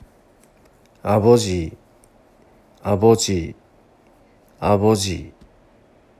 【아버지（アボジ）の呼び方】